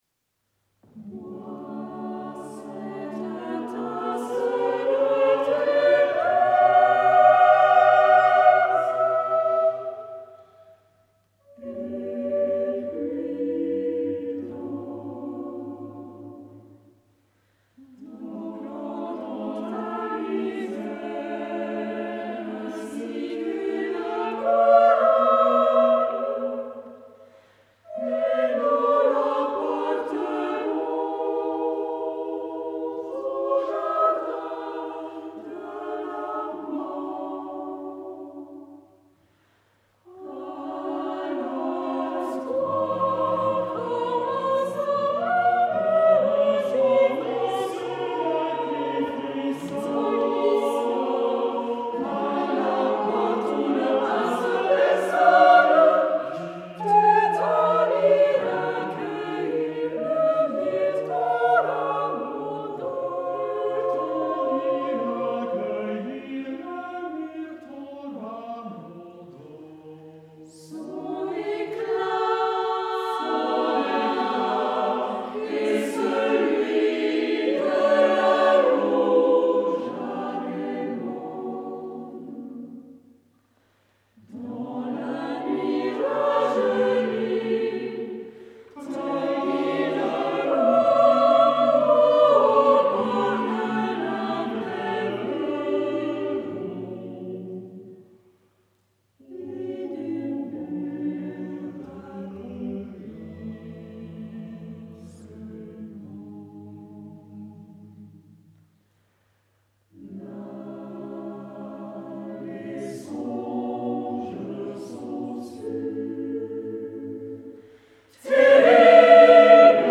Concert du 22 octobre 2017
Temple de Dombresson